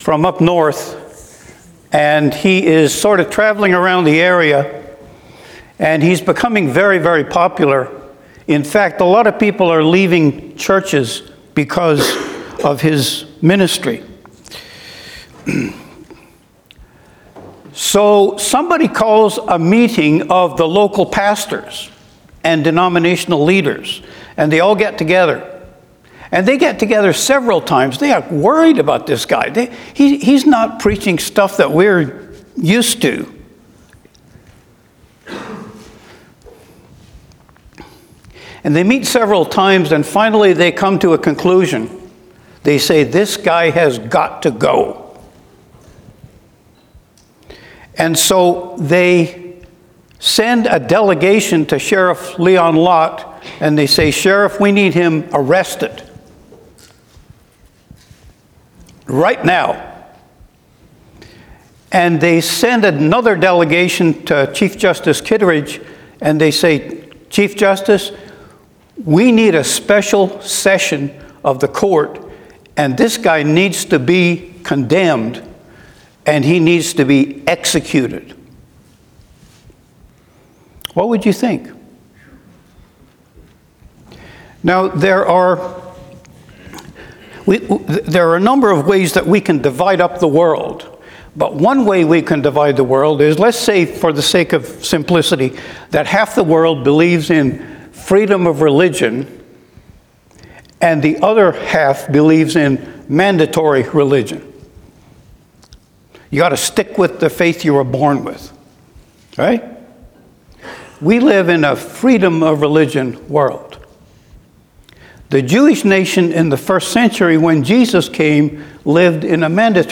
Sunday messages from a community of Christ-followers caring for and serving each other, worshiping God, and extending the grace of Jesus Christ to our community.